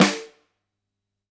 Index of /musicradar/Snares/Ludwig B
CYCdh_LudFlamB-02.wav